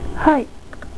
Attention: vous devez expiré de l'air en prononçant le "H" de "Hai".